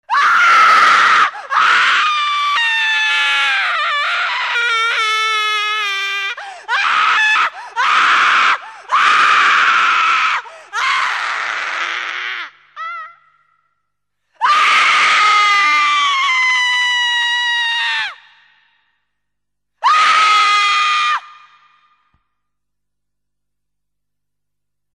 Scream 8.mp3